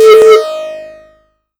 thundershield.wav